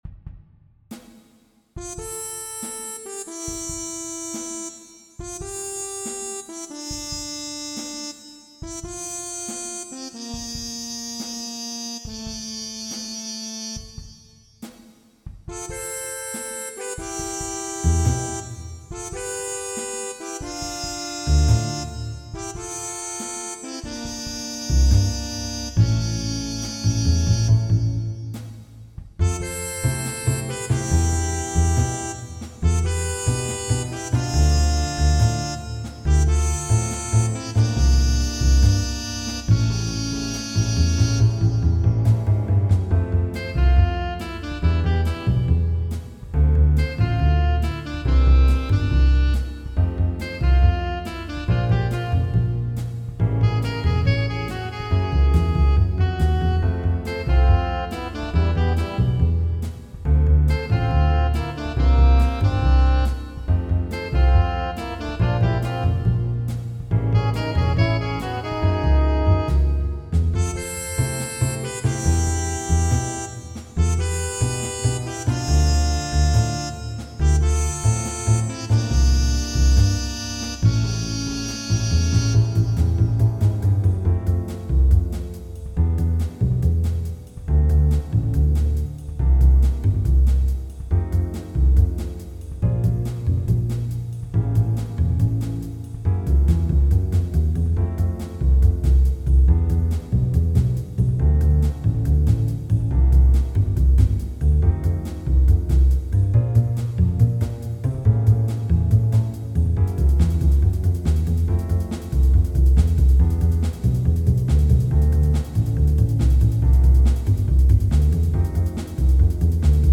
All audio files are computer-generated.